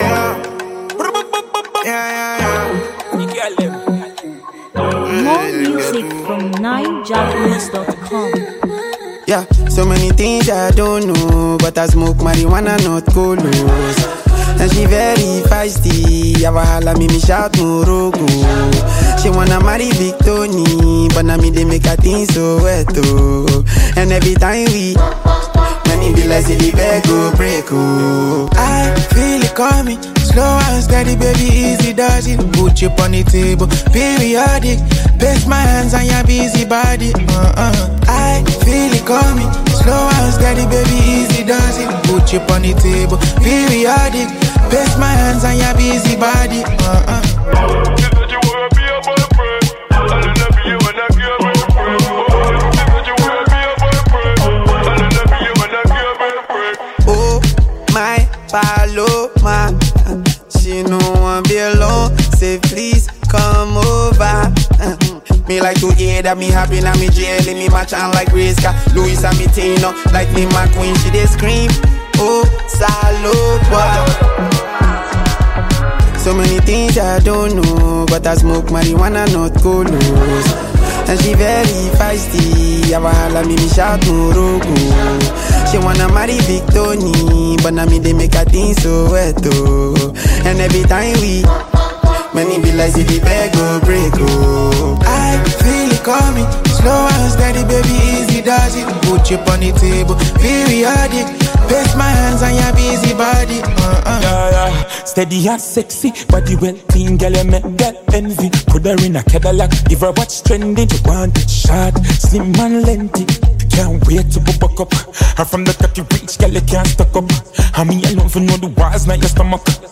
Nigerian singer and rapper